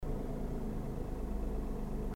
防音処理後　（ループで聞くと分かりやすいです）
音声の収録は車内の運転席（顔の位置）で収録していますよ。
音に丸みが出ているのが分かりますね。
ha36s-alto-normal_idling_mute.mp3